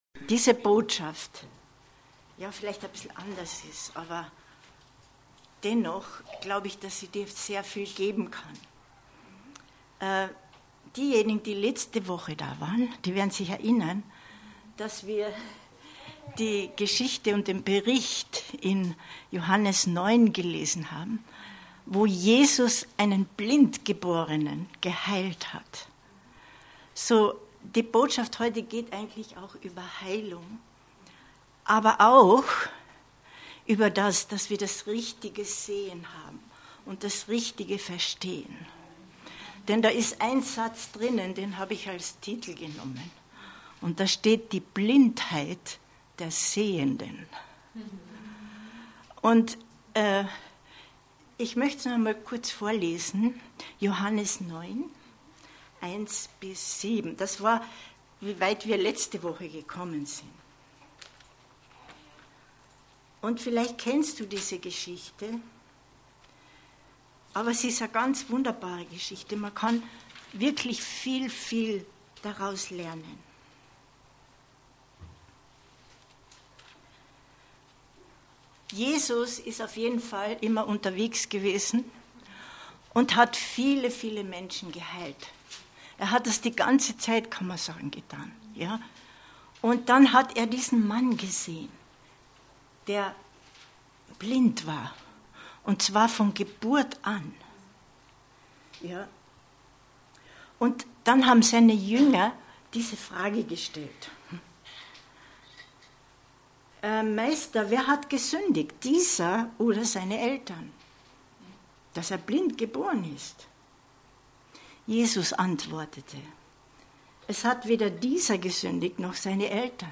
Info Info Die Blindheit der Sehenden 16.10.2022 Predigt herunterladen